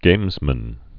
(gāmzmən)